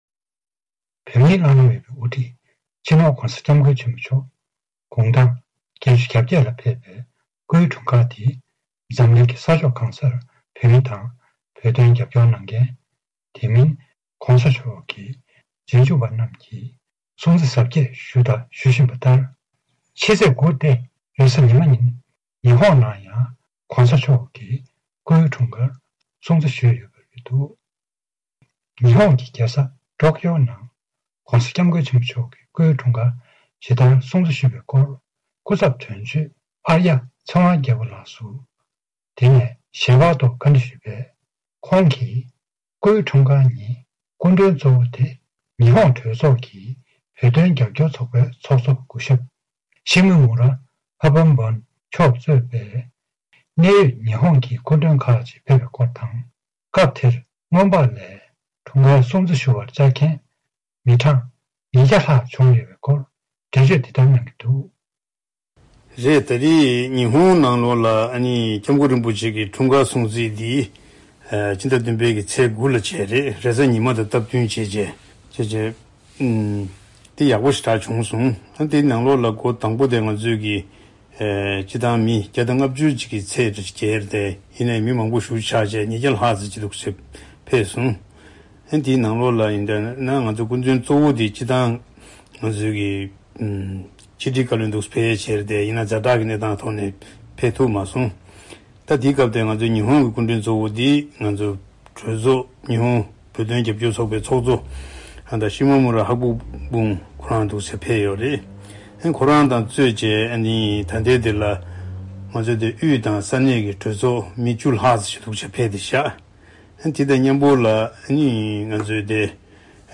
གནས་འདྲི་ཞུས་པ་ཞིག་གཤམ་ལ་གསན་རོགས་གནང་།